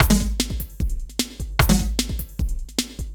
CRATE V DRUM 2.wav